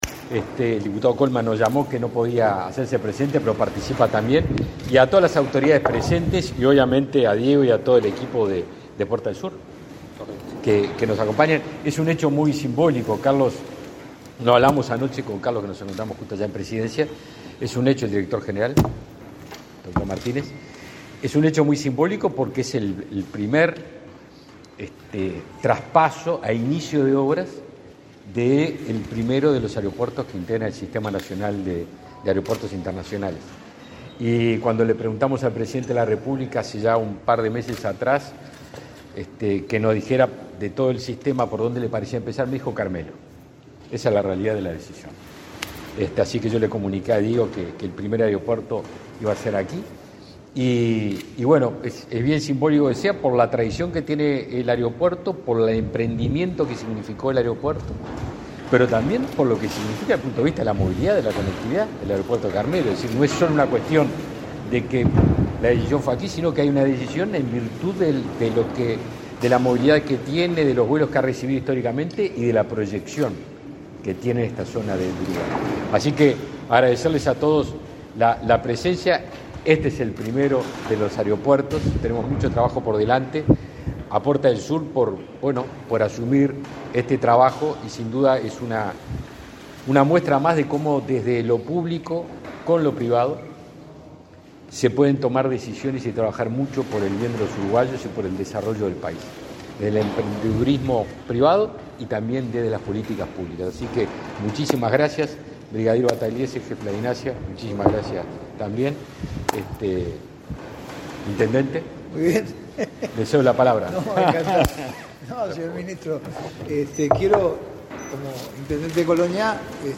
Conferencia de prensa por la firma de documentación para inicio de obras en aeropuerto de Carmelo